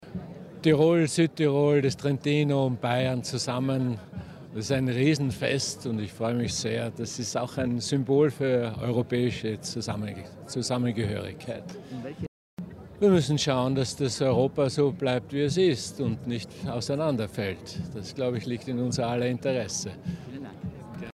Stamattina la sfilata nella Zillertal che ha concluso l'Alpenregionfest con circa 11.000 cappelli piumati
INTV ALEXANDER VAN DER BELLEN DE
INTV_ALEXANDER_VAN_DER_BELLEN_DE_(1+2).mp3